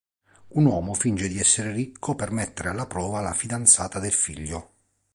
Pronounced as (IPA) /ˈrik.ko/